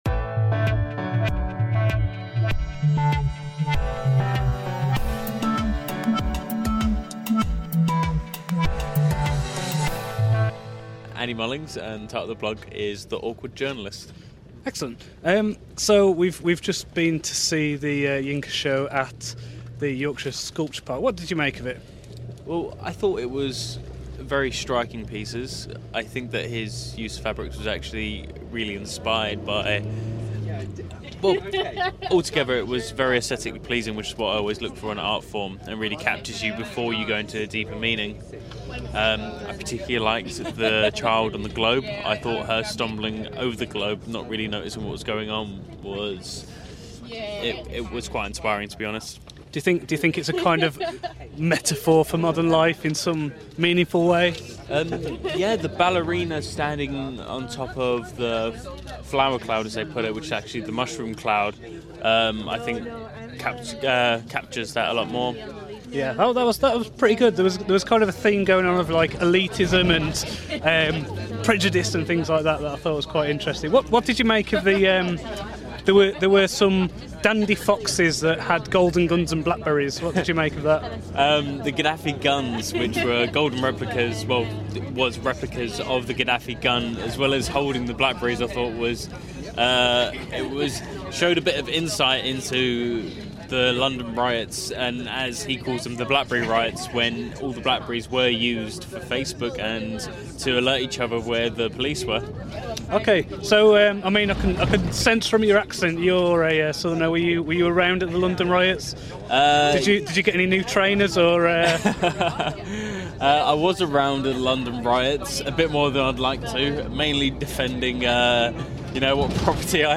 Yinka Shonibare: bloggers' reactions